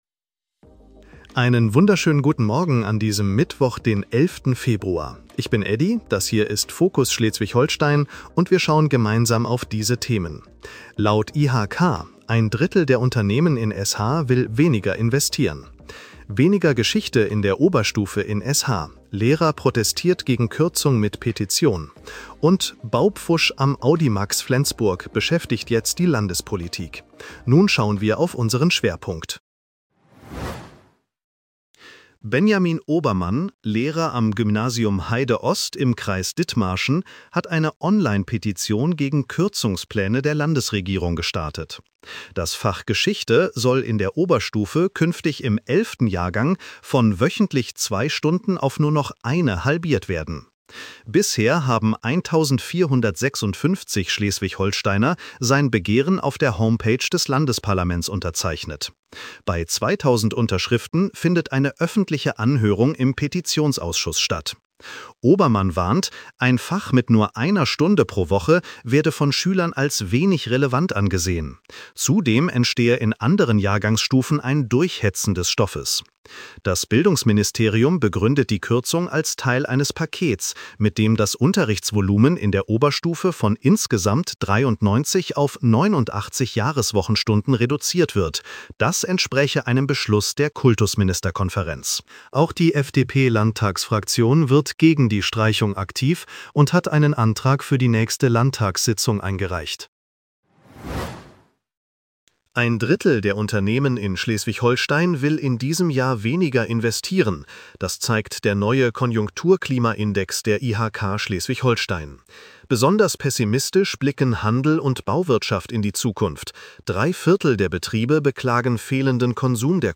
Nachrichten-Podcast bekommst Du ab 7:30 Uhr die wichtigsten Infos